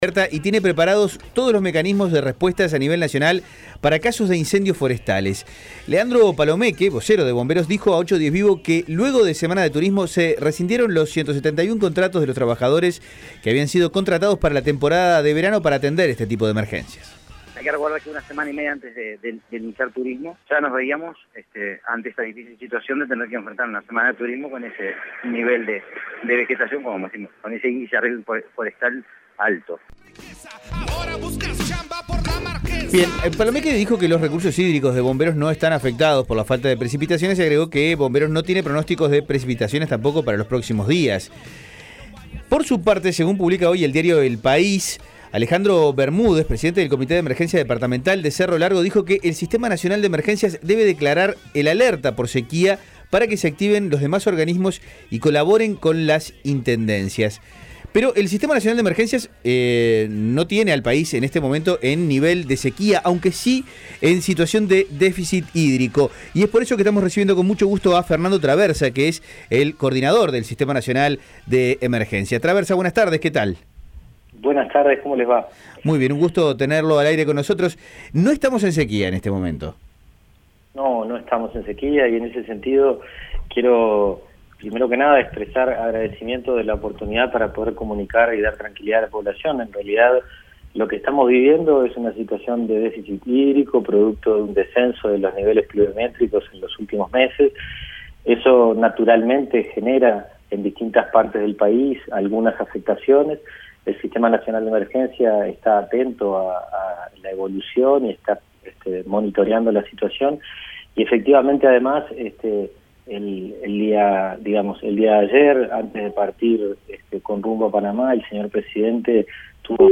El director del Sistema Nacional de Emergencias, Fernando Traversa, dijo en conversación con 810 Vivo que todavía no estamos en una situación de sequía si no que estamos frente a un déficit hídrico. Además, agregó que el Instituto de Meteorología no prevé precipitaciones para los próximos 10 días.